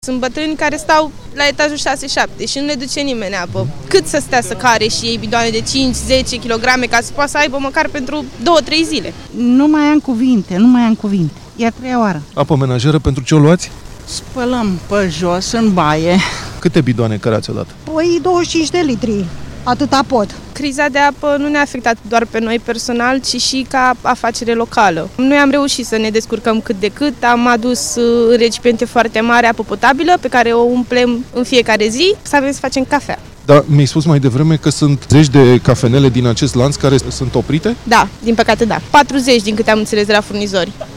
„Sunt bătrâni care stau la etajul șase–șapte și nu le duce nimeni apă”, spune o localnică
04dec-15-vox-localnici-Campina.mp3